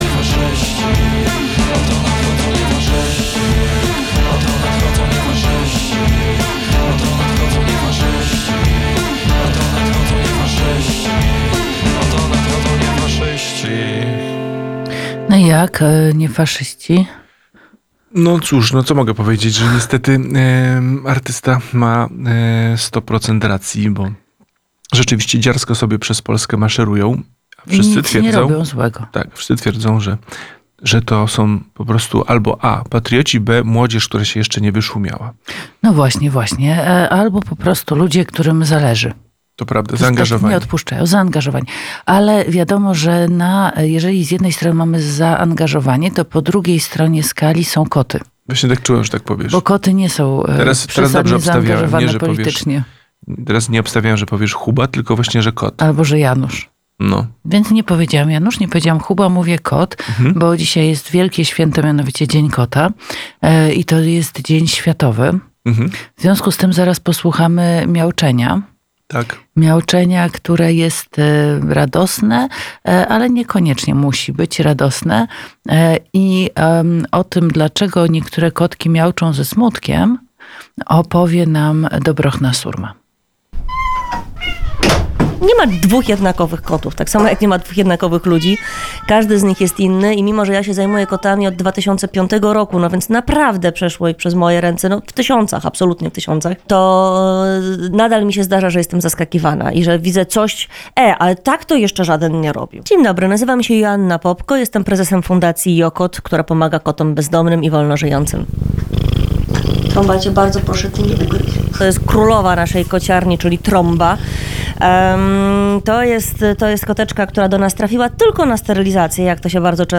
Jesteście ciekawi, co mamy do powiedzenia o kotach i co same koty mruczały do mikrofonu?